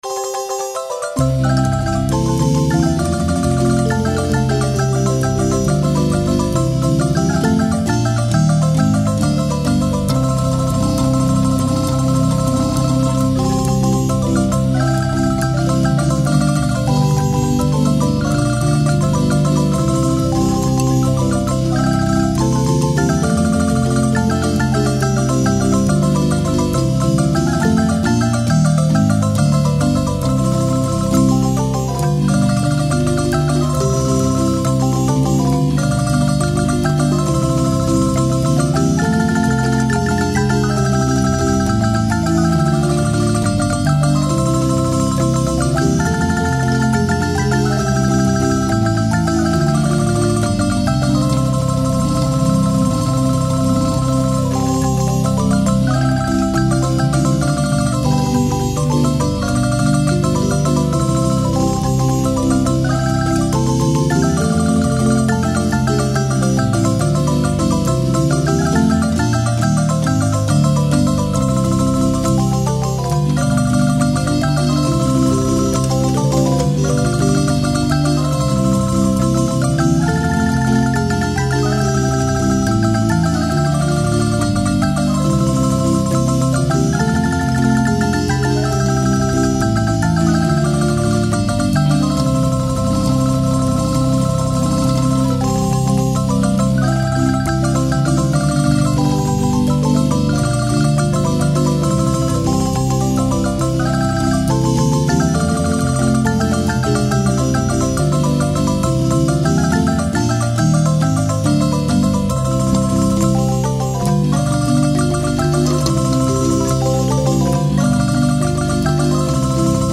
Las obras, ordenadas de 1 a 8, fueron grabadas en estudio durante el año 2006 con la marimba escuadra (marimba grande y un tenor) de la Casa de la Cultura de Santa Cruz, Guanacaste.
MUSICA, GUANACASTE, MARIMBA